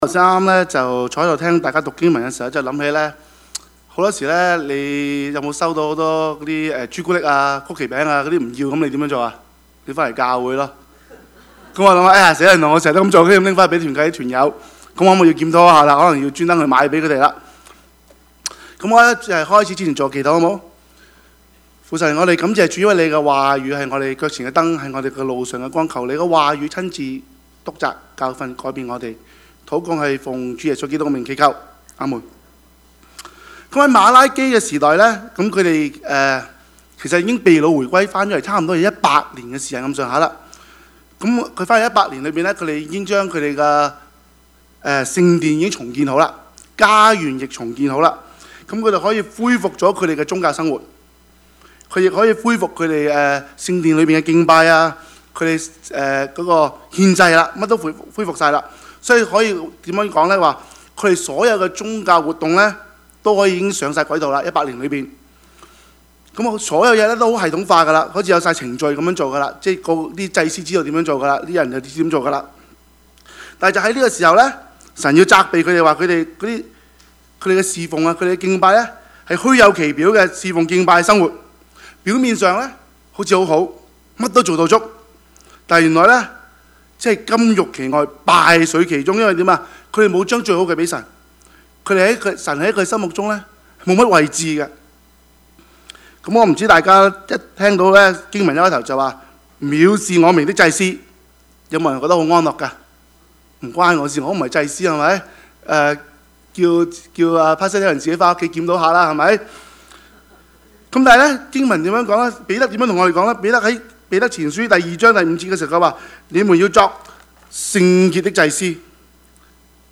Service Type: 主日崇拜
Topics: 主日證道 « 神是信實的, 我們呢?